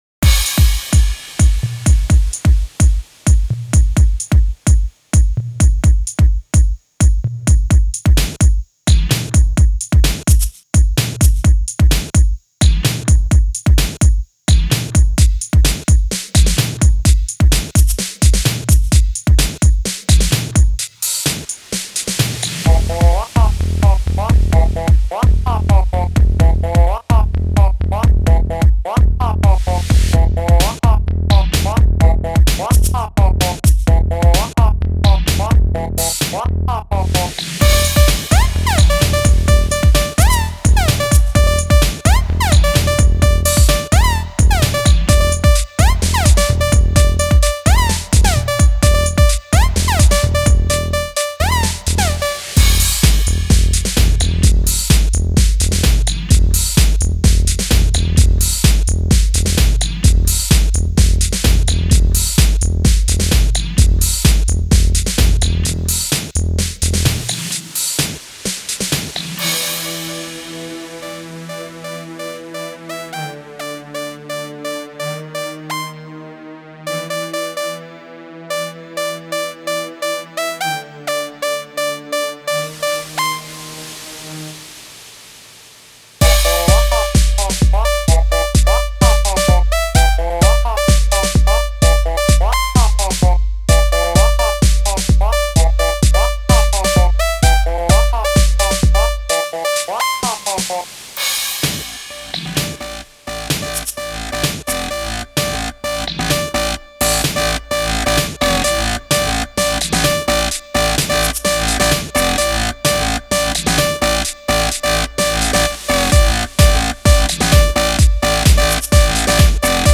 Это дач хаус) именно так я считаю он должен звучать) Довольнотаки пазитивненько) Законченный трек.